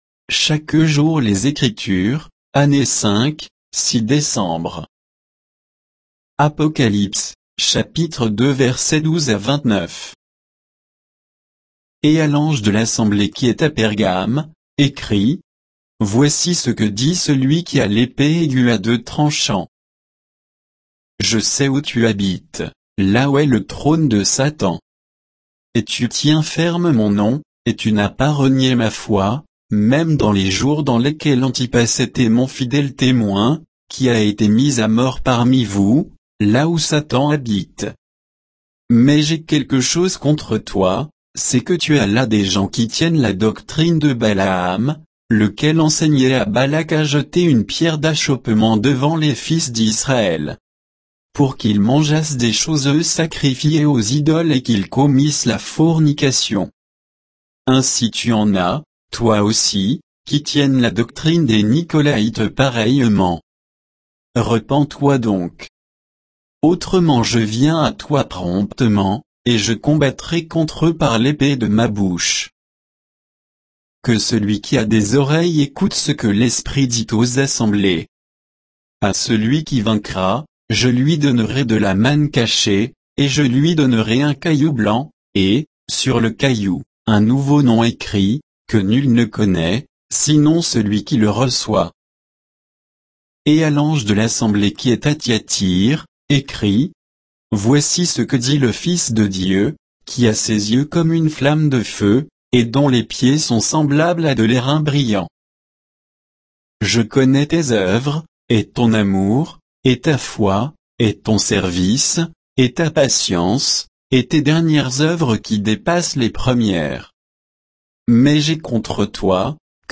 Méditation quoditienne de Chaque jour les Écritures sur Apocalypse 2, 12 à 29